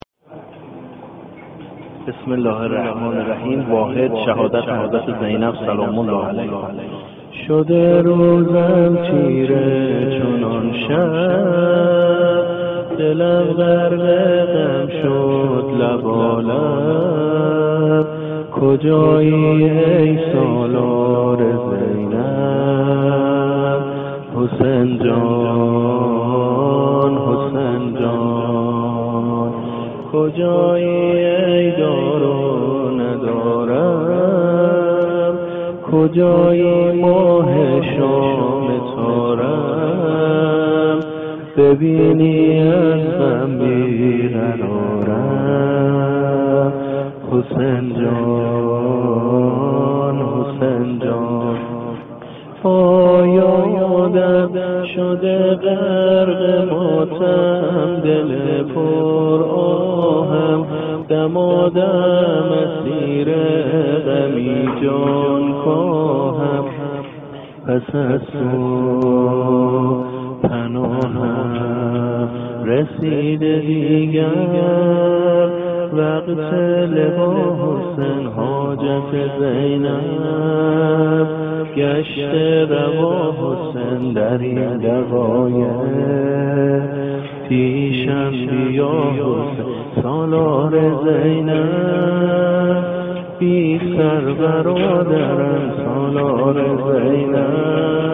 واحد وفات حضرت زینب (س) در نیمه ماه رجب -( شده روزم تيره چنان شب )